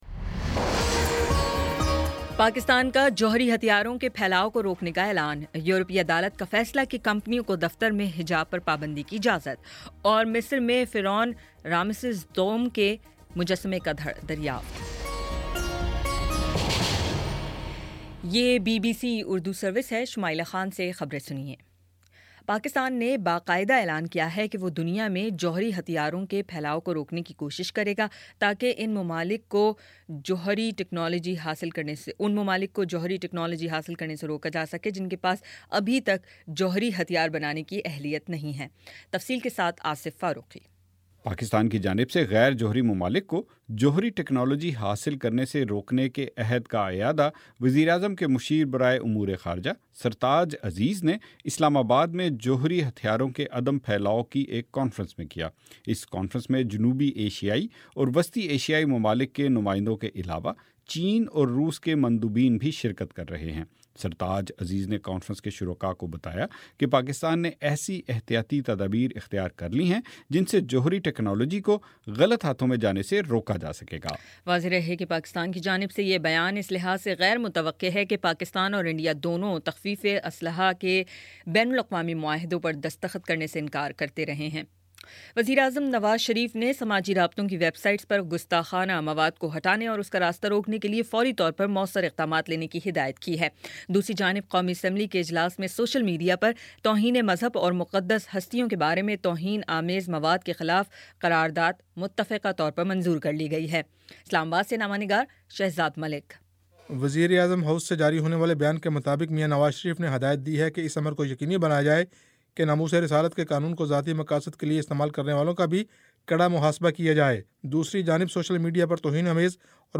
مارچ 14 : شام چھ بجے کا نیوز بُلیٹن